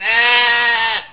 pecora.au